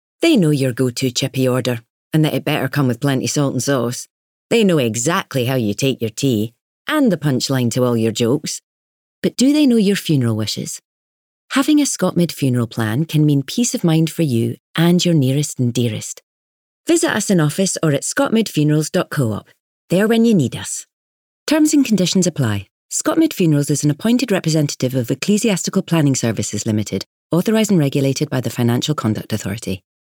Radio advert
Once the adverts had been approved, the recording studio Finiflex in Leith was booked and the two adverts were recorded.